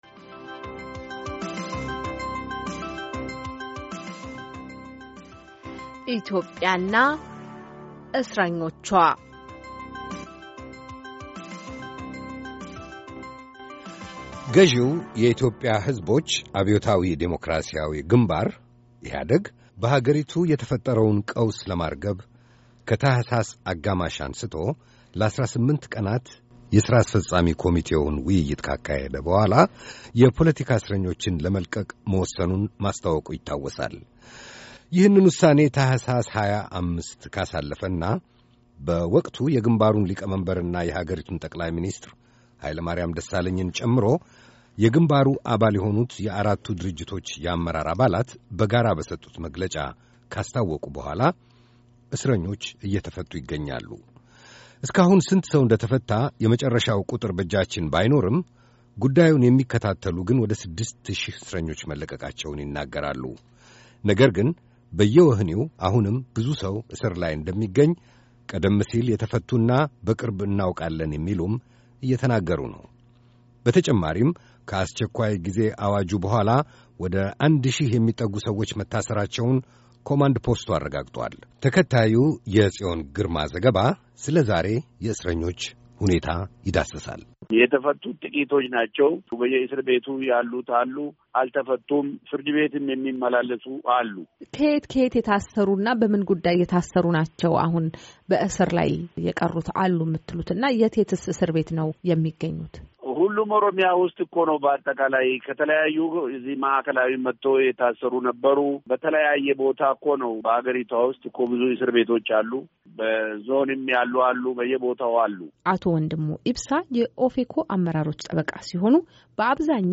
በኢትዮጵያ በአማራ፣ በኦሮሚያ፣ በትግራይና በኮንሶ የታሰሩ እስረኞችን በተመለከተ የተጠናቀረ ዘገባ።